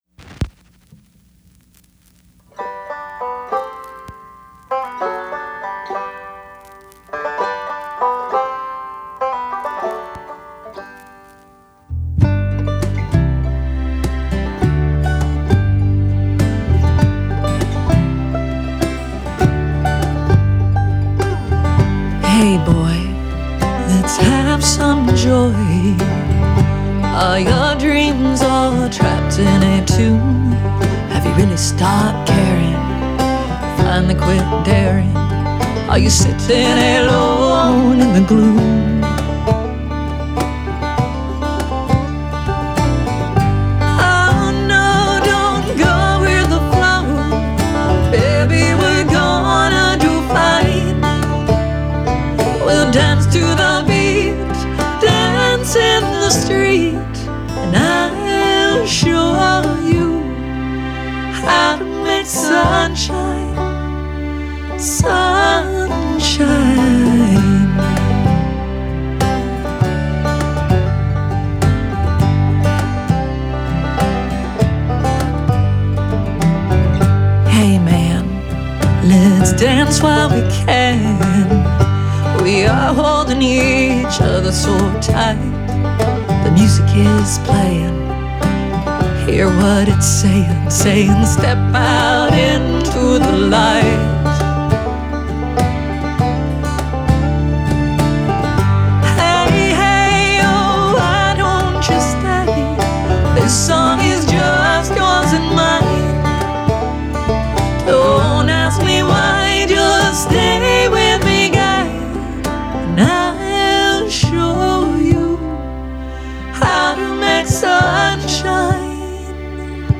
Genre: Alternative Folk, Singer/Songwriter, Country